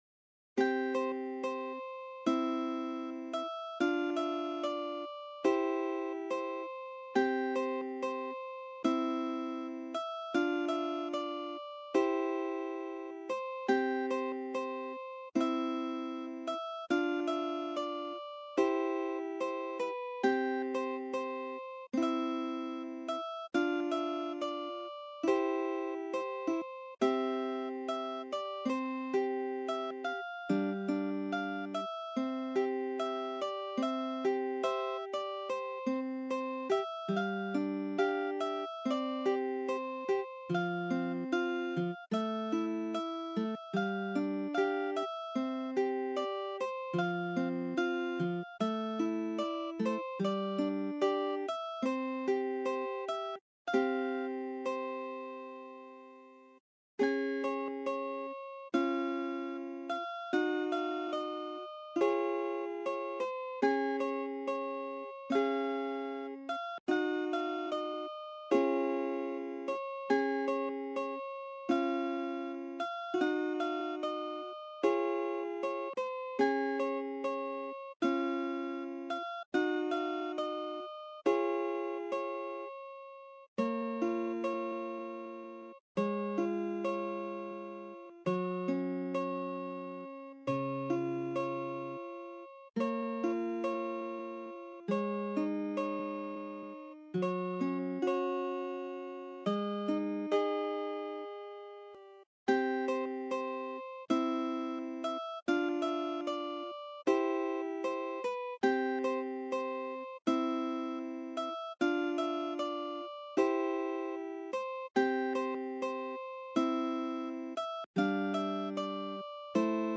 Bright, happy
cheerytune.ogg